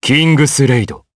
DarkKasel-Vox_Kingsraid_jp.wav